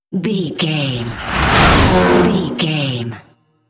Sci fi whoosh electronic flashback
Sound Effects
Atonal
futuristic
intense
whoosh